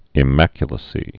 (ĭ-măkyə-lə-sē)